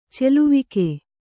♪ celuvike